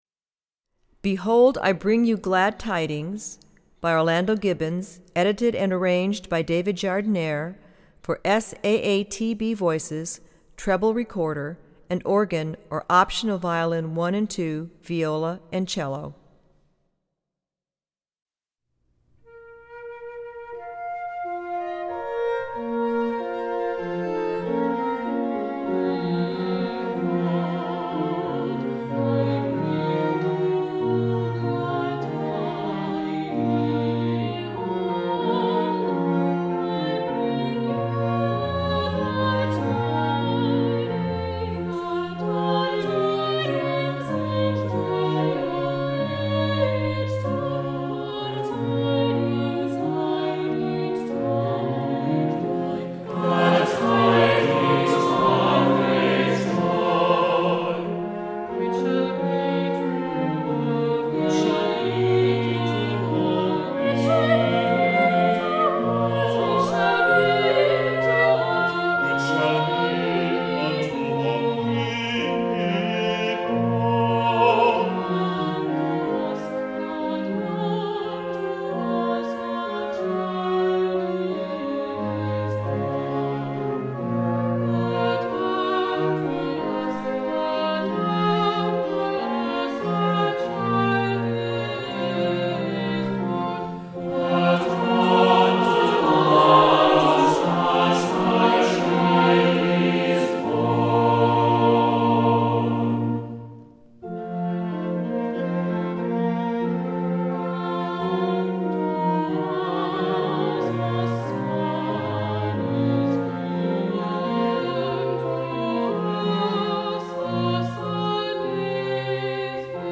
Voicing: SAATB